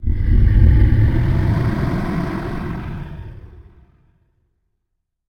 lowgrowl.ogg